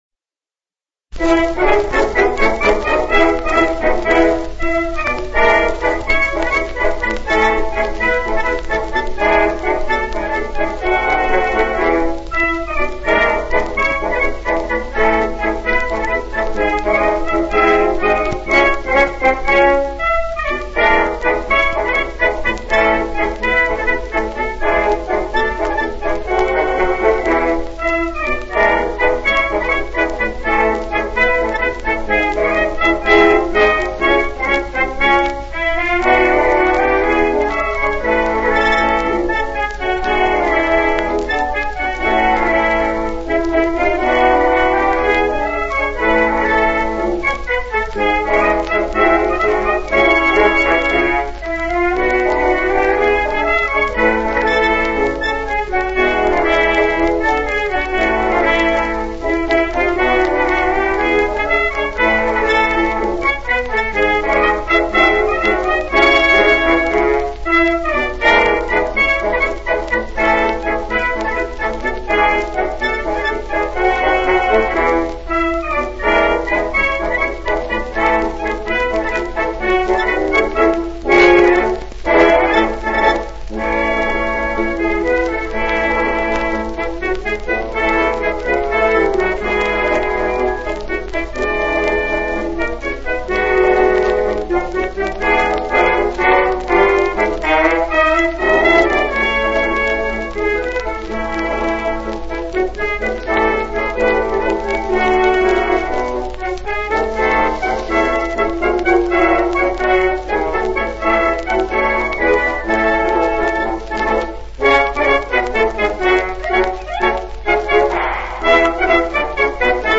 Suffrage March
recorded on July 15, 1914 in Camden, New Jersey